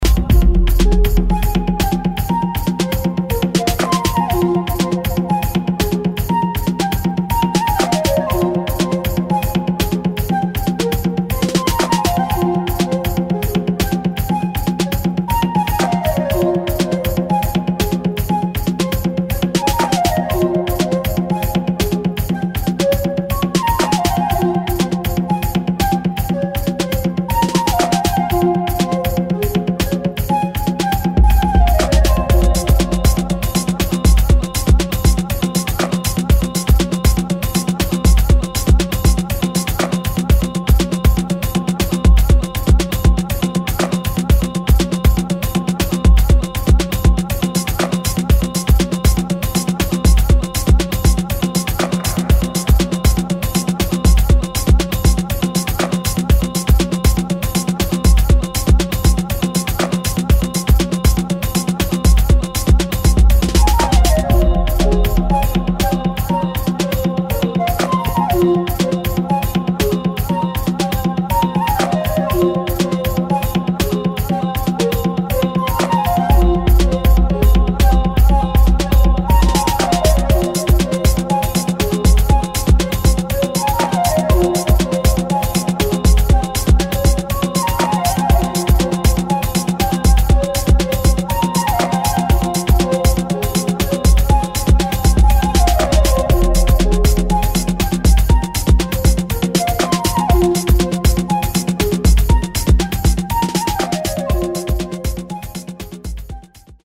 [ ELECTRONIC / HOUSE / TECHNO ]